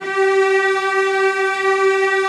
CELLOS AN4-L.wav